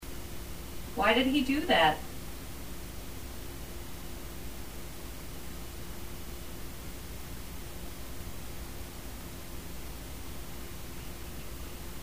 Right Bedroom – 9:34 pm
There is a noise in response to the question “Why did he do that?”